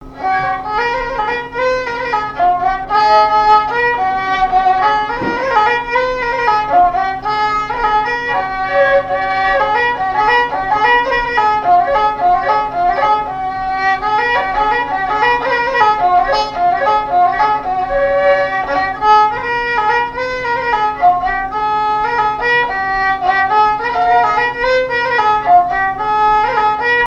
Chants brefs - A danser
danse : gigouillette
Répertoire de marches de noce et de danse
Pièce musicale inédite